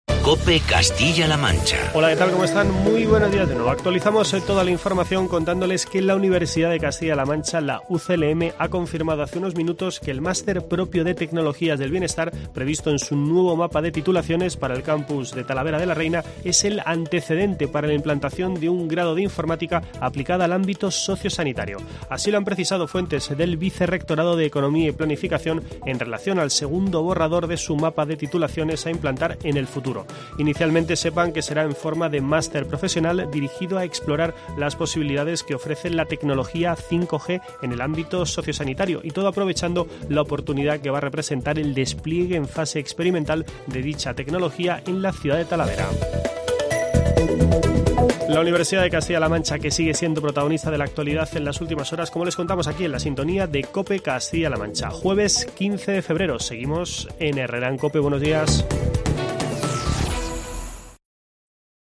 Boletín informativo de COPE Castilla-La Mancha en este jueves, 15 de febrero de 2018.